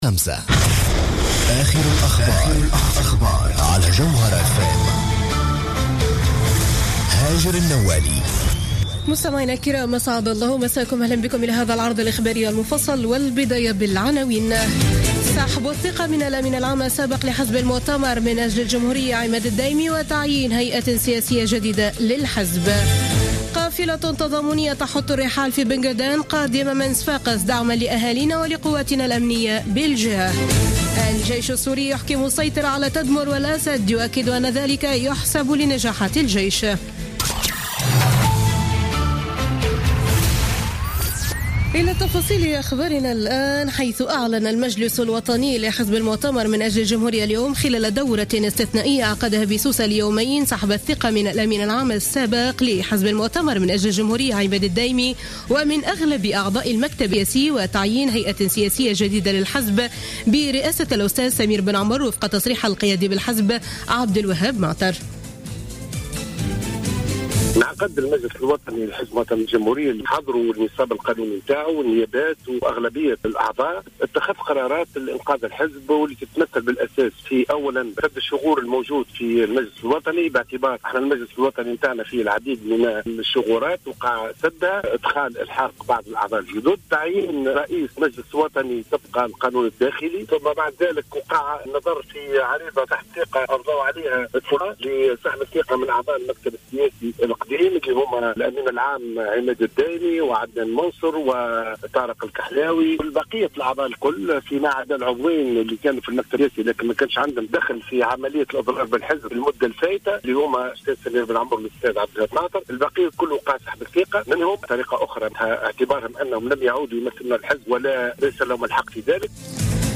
نشرة أخبار السابعة مساء ليوم الأحد 27 مارس 2016